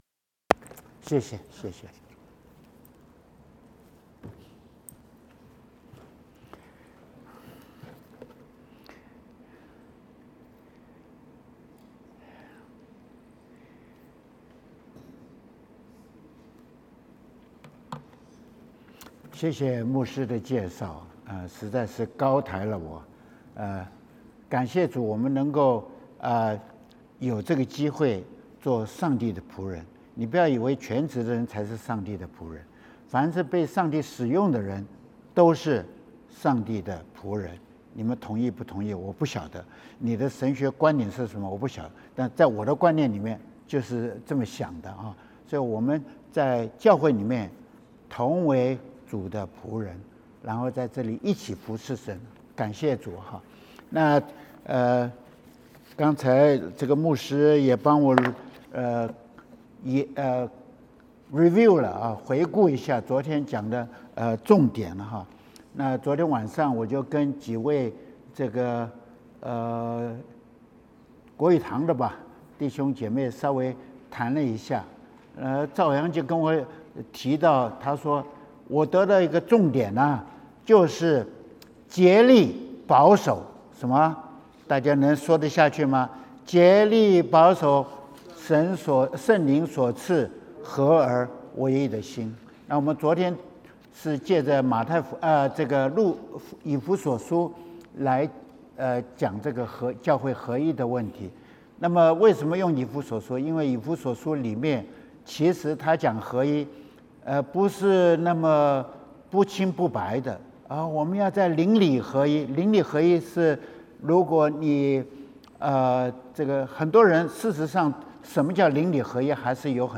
Chinese Sermons | Chinese Christian Church of Greater Washington DC (en)
2024 Summer Retreat